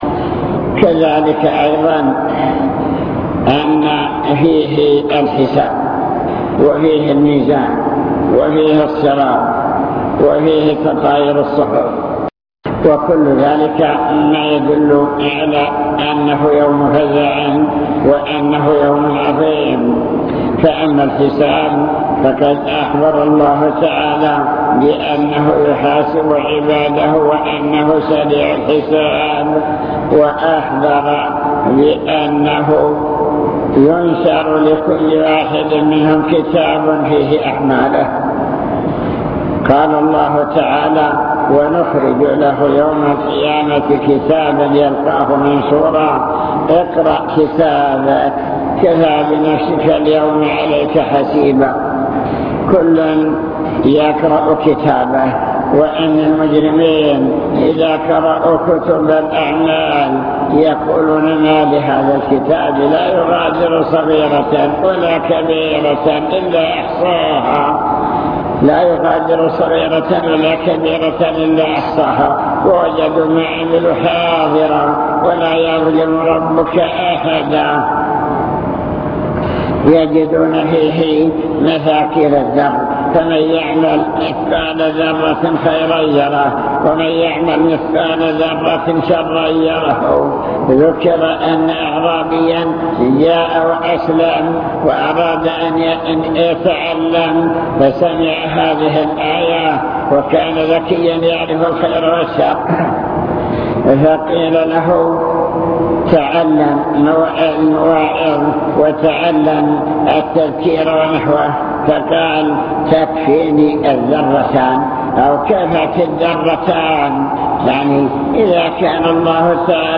المكتبة الصوتية  تسجيلات - محاضرات ودروس  مواعظ وذكرى